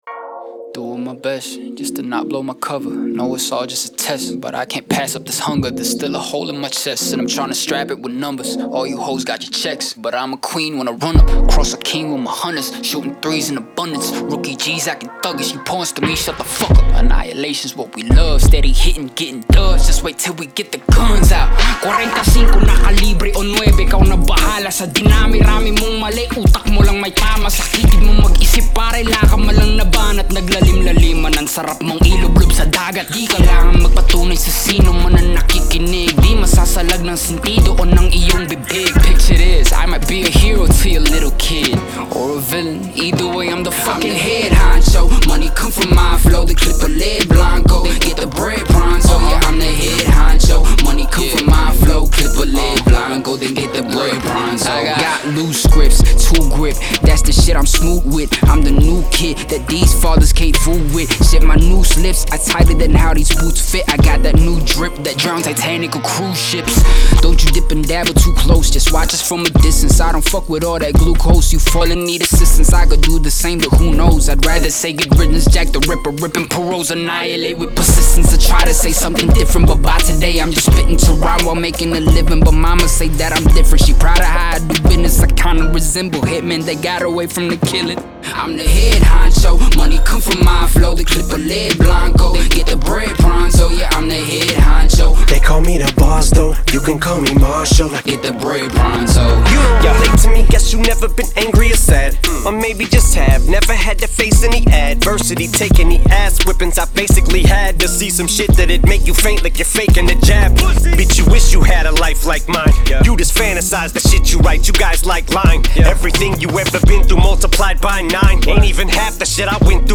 • Жанр: Hip-Hop, Rap